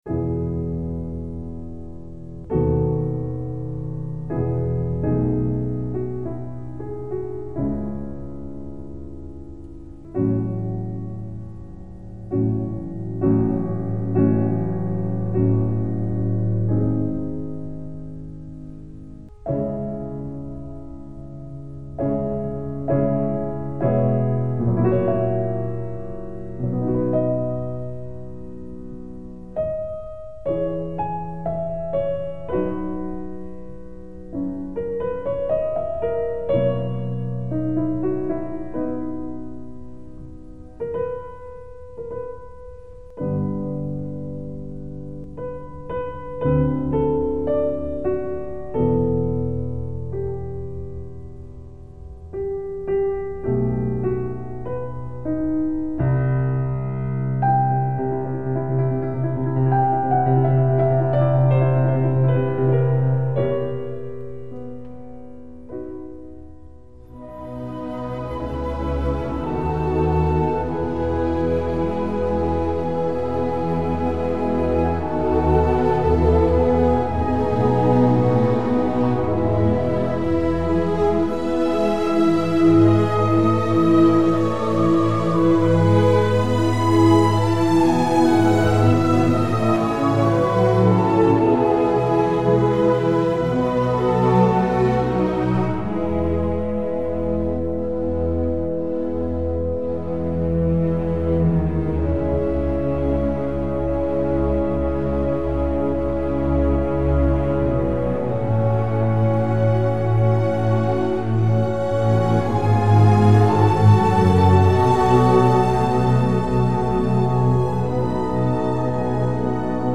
BEETHOVEN, concerto pour piano n3, 02 Largo - MONET (Claude), soleil couchant a Lavacourt.mp3